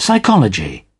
Transcription and pronunciation of the word "psychology" in British and American variants.